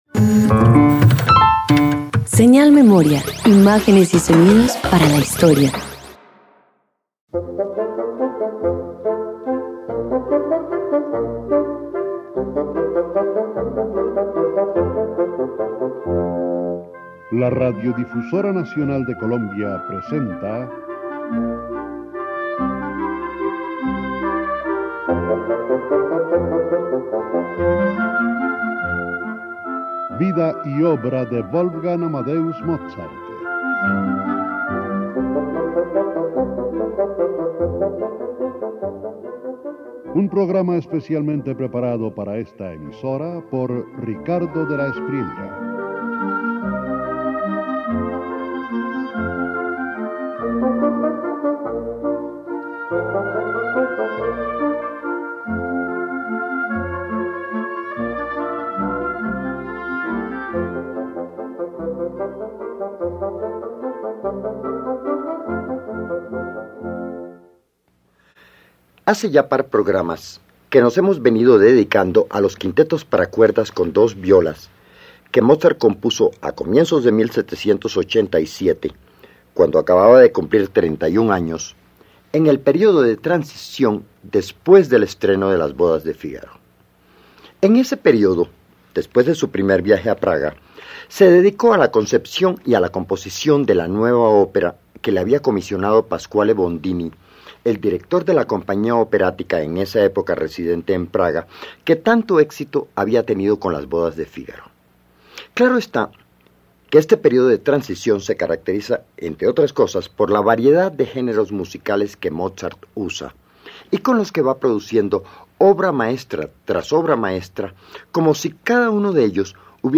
261 Quintetos para cuerdas Parte II_1.mp3